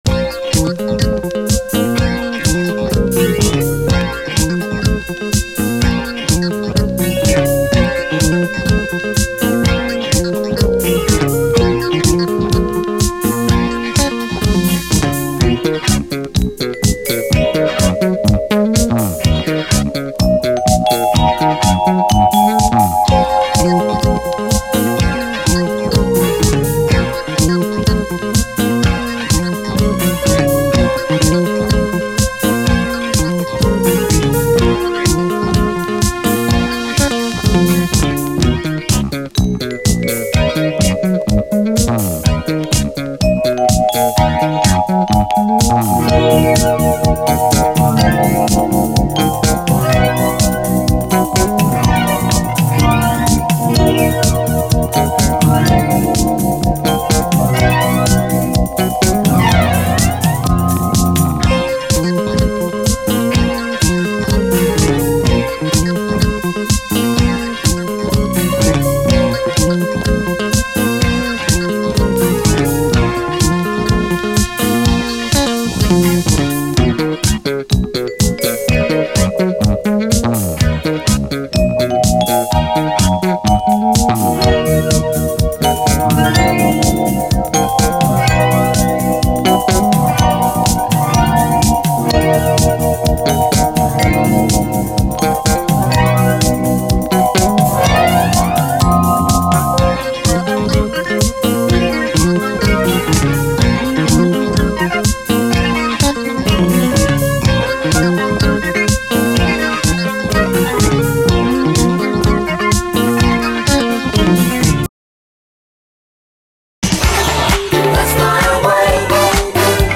SOUL, 70's～ SOUL, DISCO
隠れたスペイシー・ブリット・ファンク〜ジャズ・ファンク・トラック！
UKのプロデューサー・コンビによるディスコ・プロジェクト！